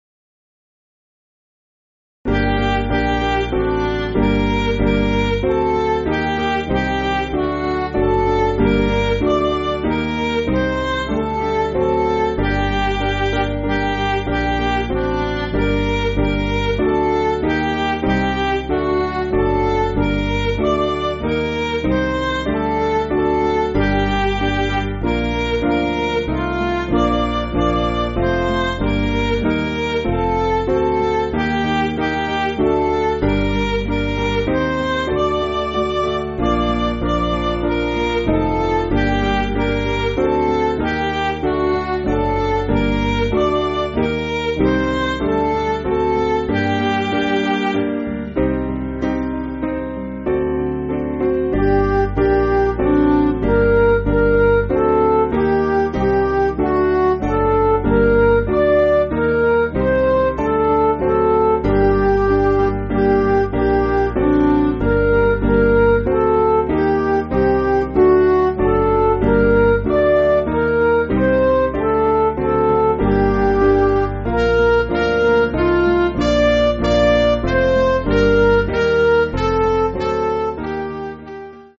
Piano & Instrumental
(CM)   2/Gm